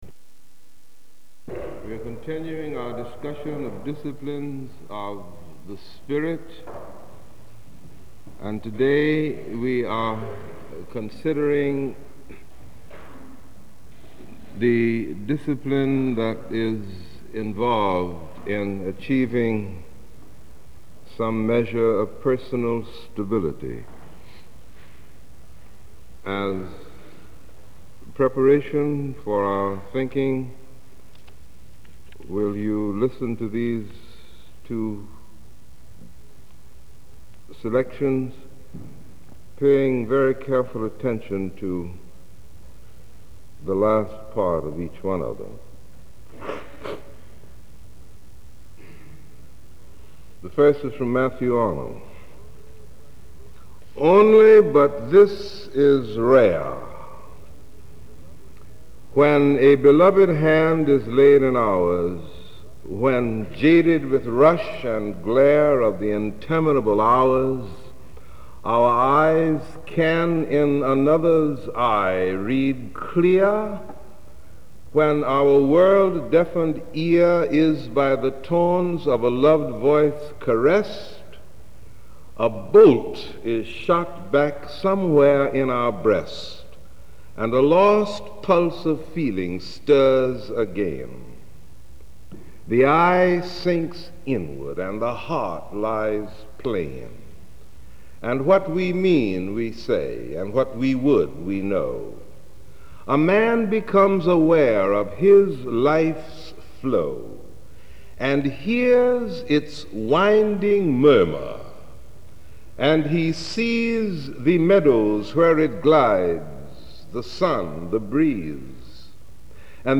Third in a series of sermons on Spiritual Disciplines preached at Marsh Chapel, Boston University in 1960.